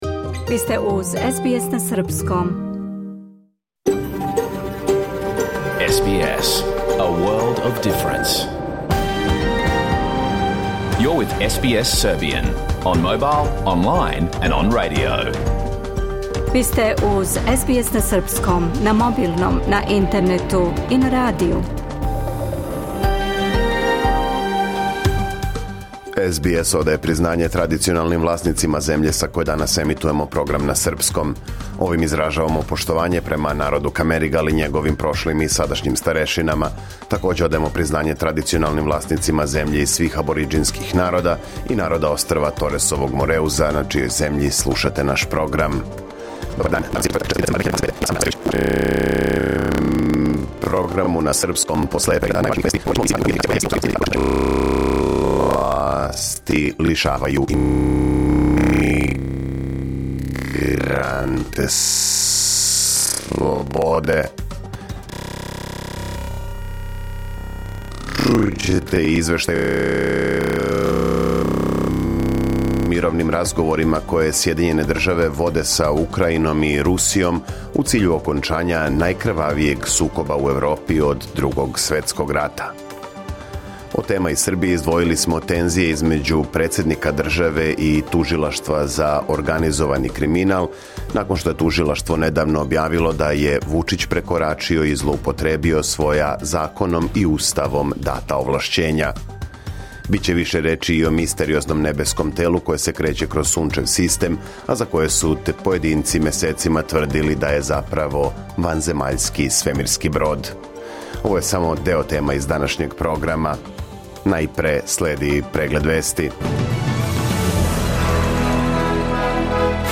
Програм емитован уживо 4. децембра 2025. године